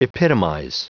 Prononciation du mot : epitomize